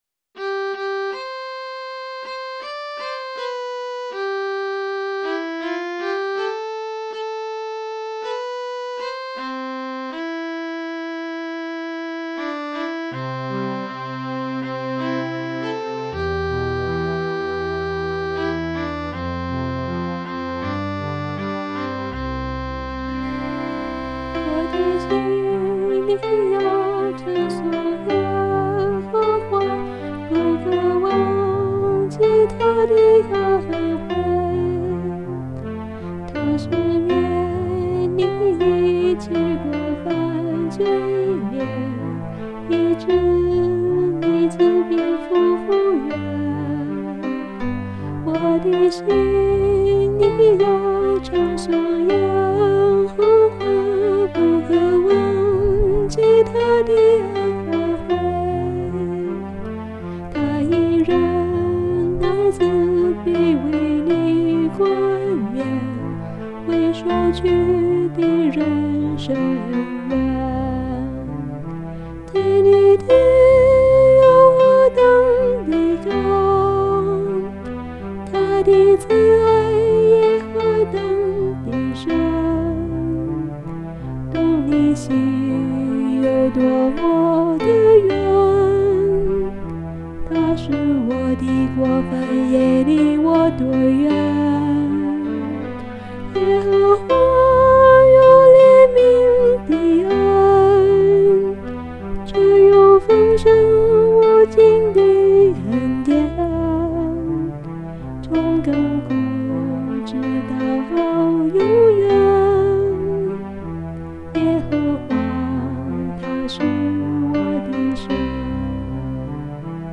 This is a where I keep the karaoke songs I did.